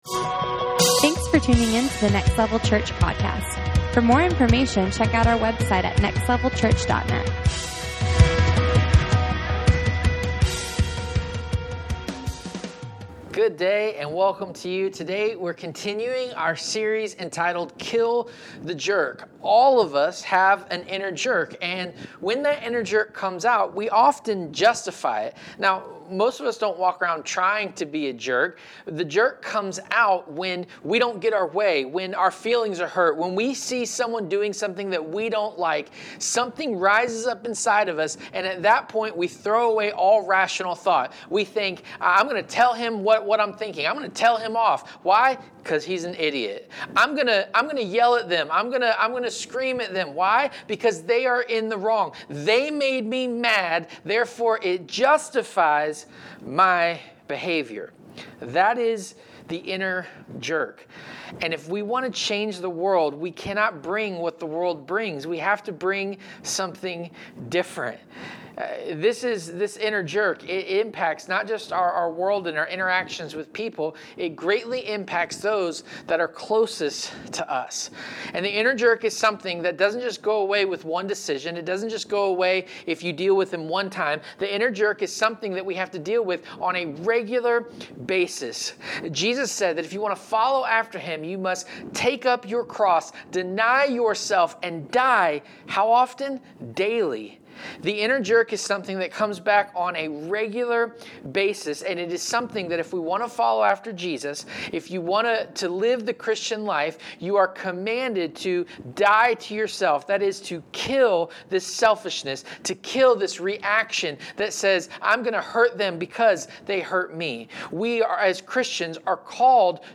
Kill The Jerk Service Type: Sunday Morning Watch We all know a jerk.